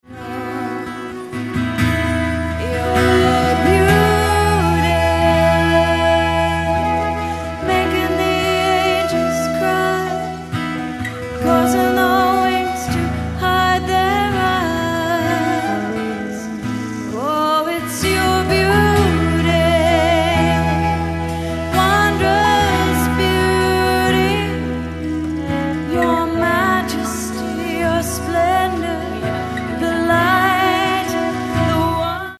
(MP3 Worship Download)